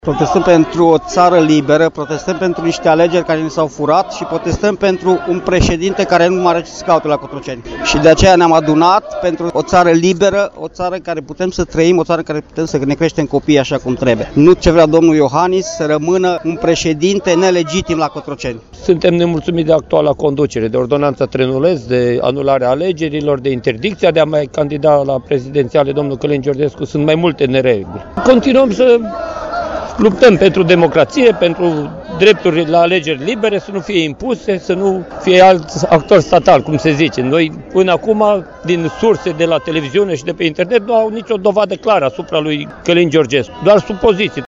VOX-URI-PROTEST-AUR-17.mp3